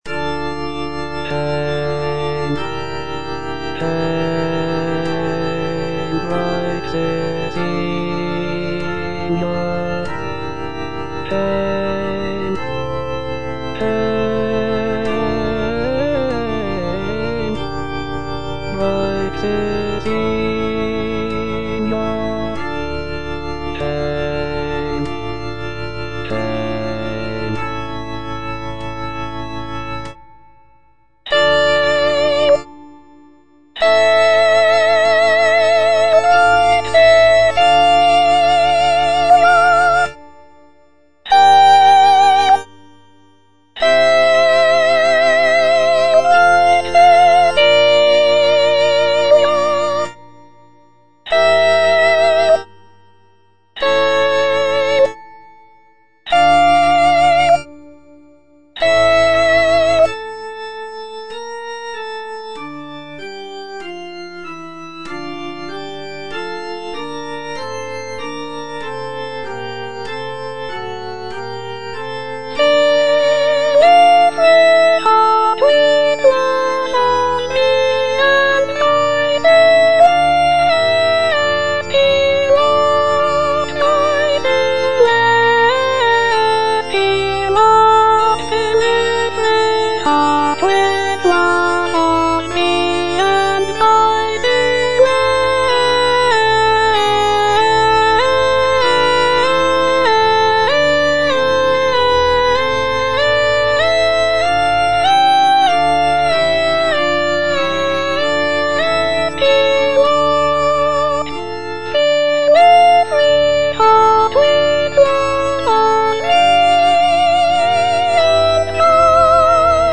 H. PURCELL - ODE FOR ST. CECILIA’S DAY, 1692 Hail, bright Cecilia (I) - Soprano (Voice with metronome) Ads stop: auto-stop
The work features a mix of vocal solos, choral sections, and instrumental music, showcasing Purcell's mastery of both sacred and secular music.